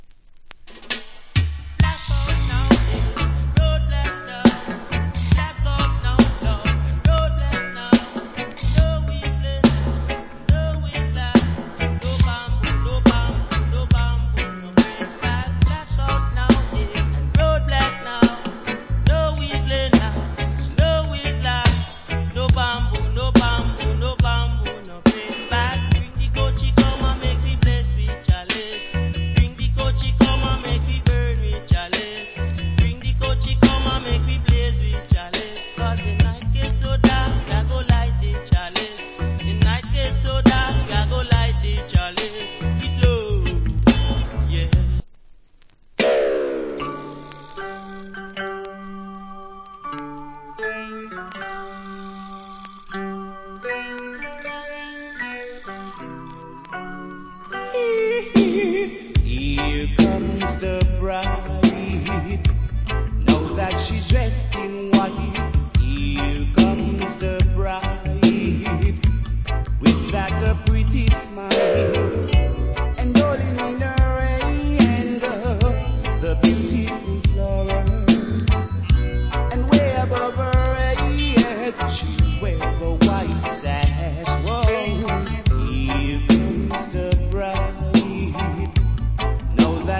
Wicked Reggae Vocal.